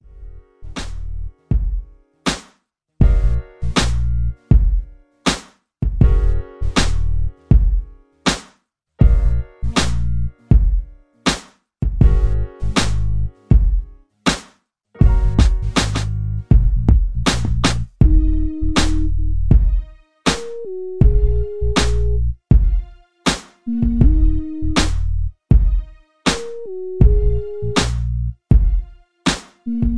East Coast Hip Hop Beat